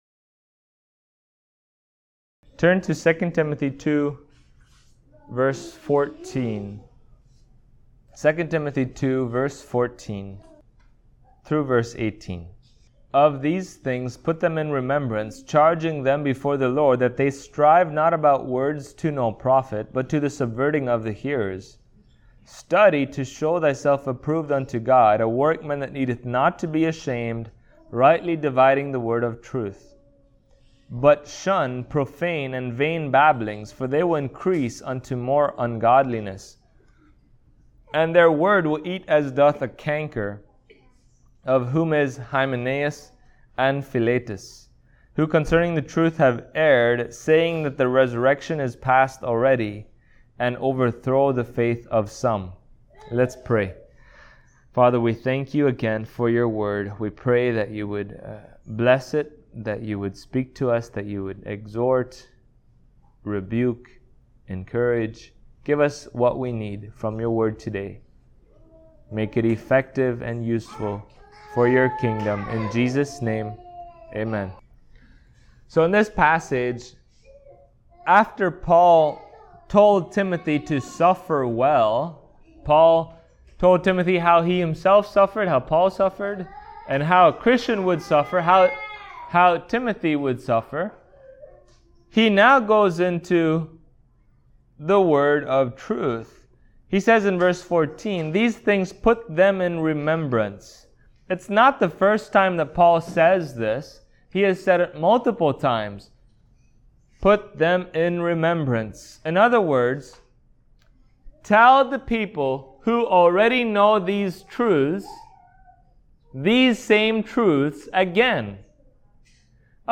Passage: 2 Timothy 2:14-18 Service Type: Sunday Morning